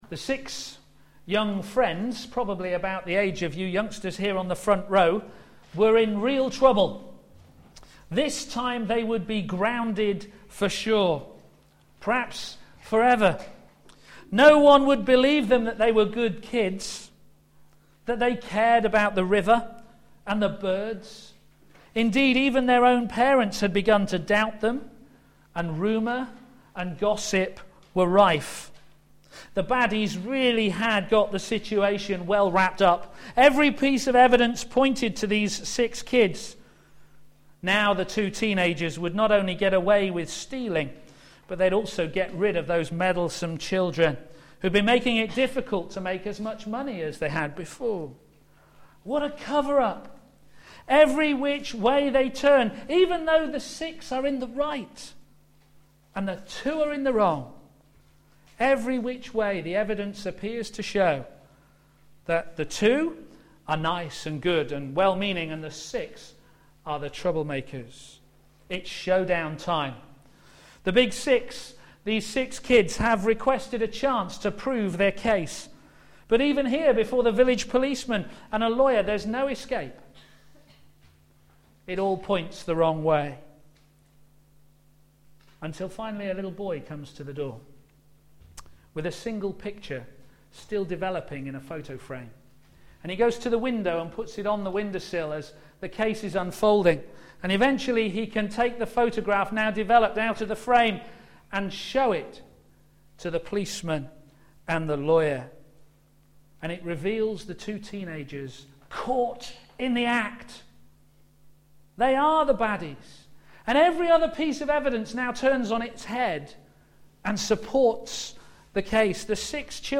a.m. Service
Theme: Christ Admired for His Convincing Resurrection Sermon